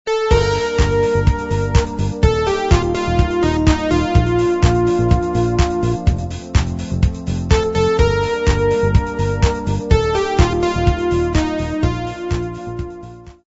Заказ полифонической версии:
• Пример мелодии содержит искажения (писк).